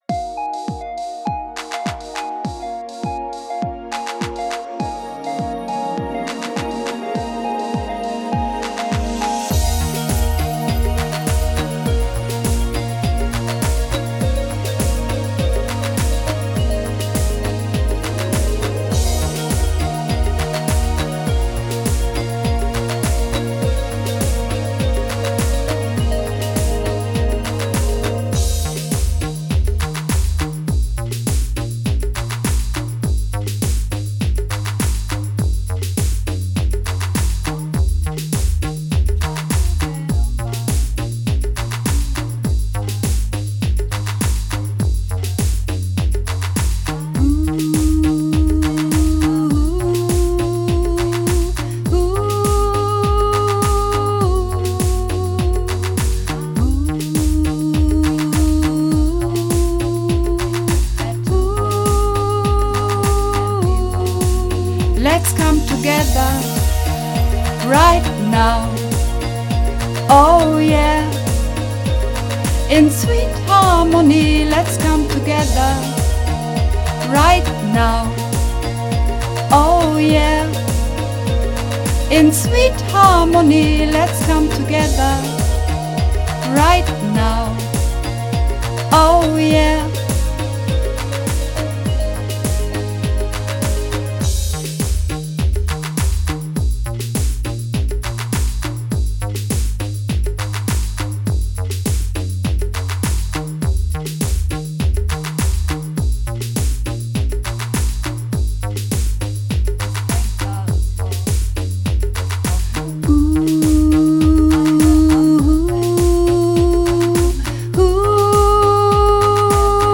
Übungsaufnahmen - Sweet Harmony
Sweet Harmony (Sopran 1)